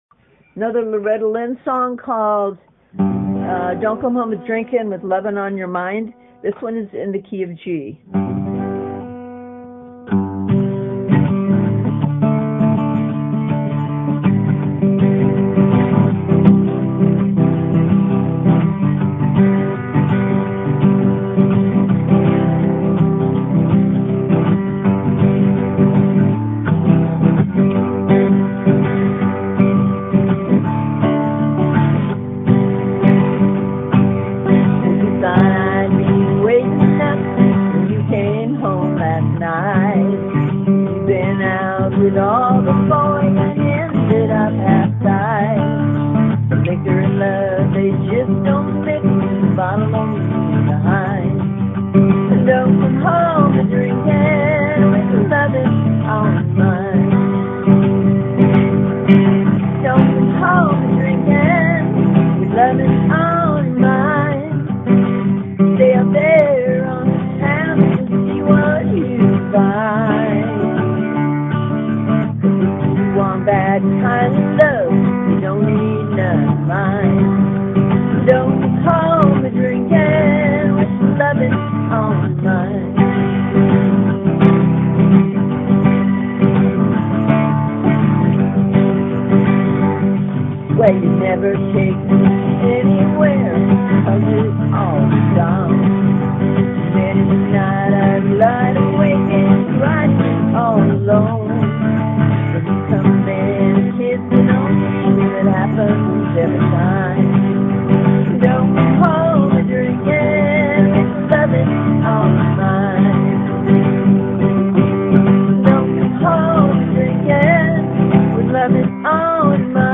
[ACAPELLA]   C.G.D.G.